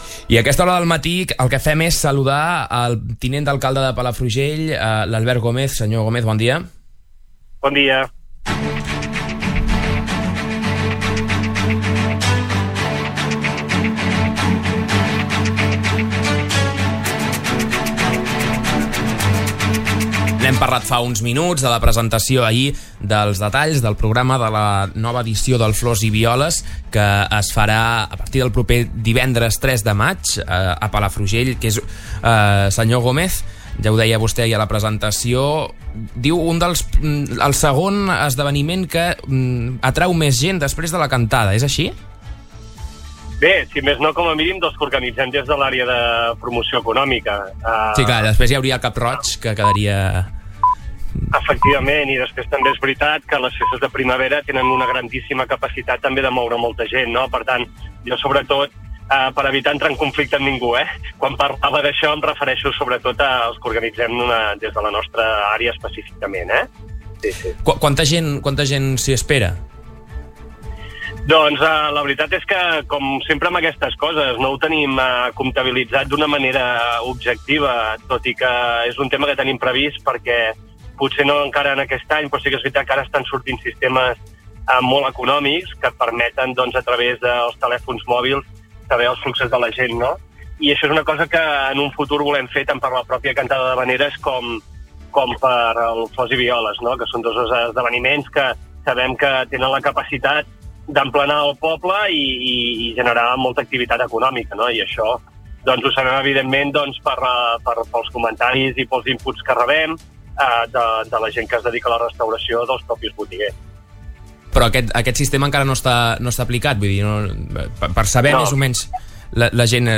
En una entrevista al Supermatí, ha explicat que de totes maneres està “més tranquil” perquè les obres del carrer La Lluna ja hauran finalitzat i això farà que no es col·lapsi tant, com per exemple va passar per Setmana Santa.
gomez-entrevista.mp3